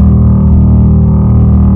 rumble_rf1V8_ex.wav